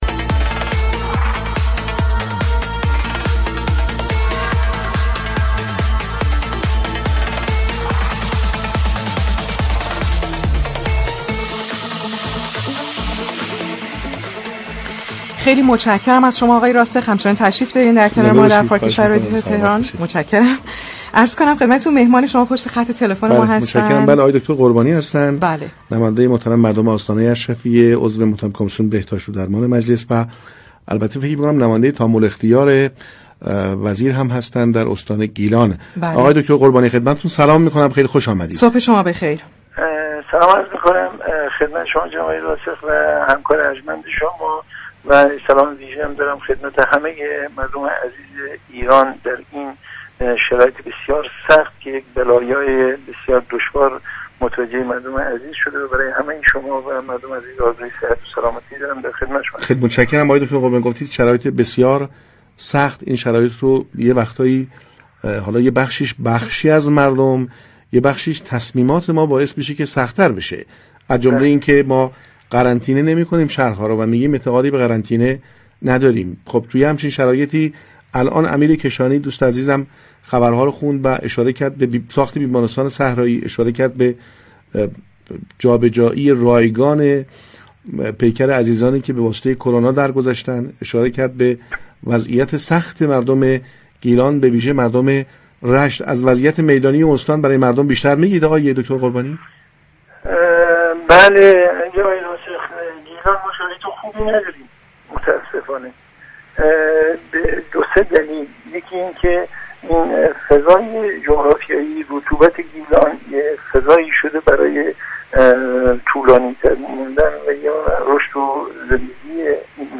محمدحسین قربانی، نماینده تام‌الاختیار وزارت بهداشت در استان گیلان در گفتگو با پارك شهر از شرایط بحرانی این استان در مواجهه با ویروس كرونا خبر داد.